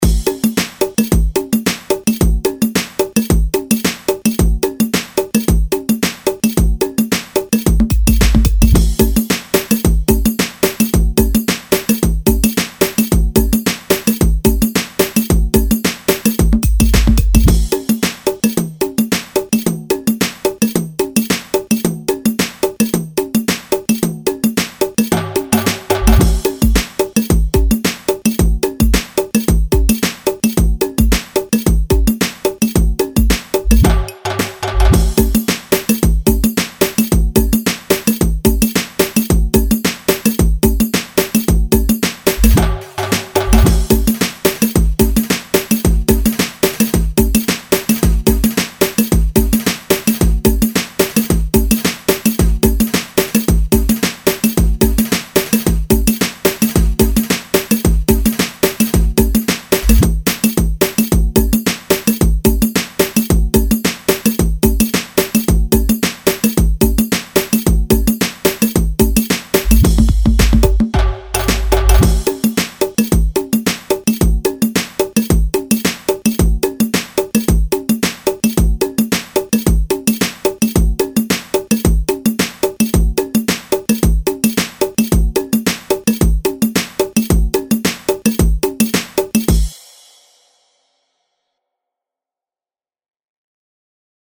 [ 108 BPM ]